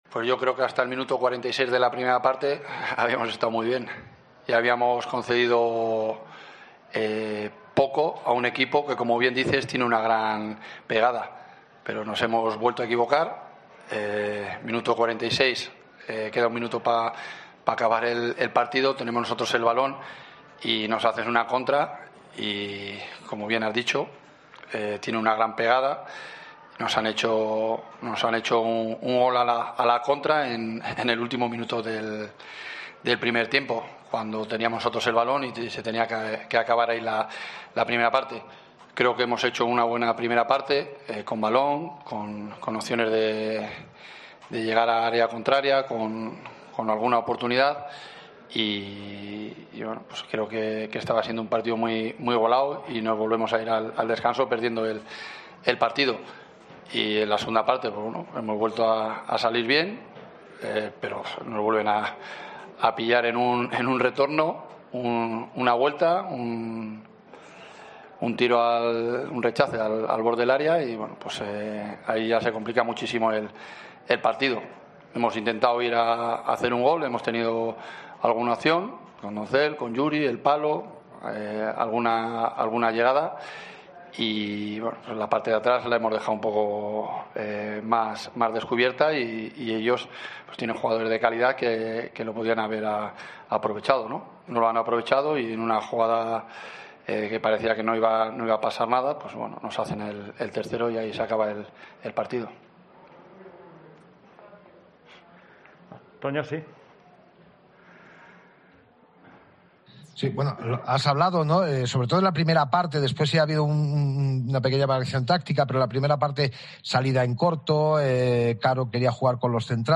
Tras cada partido de la Deportiva Ponferradina la web de COPE Bierzo te ofrece todo lo que se ha dicho en sala de prensa con las voces de los protagonistas. Lo hacemos con el mejor sonido y también con la posibilidad de poderlo ver en formato vídeo.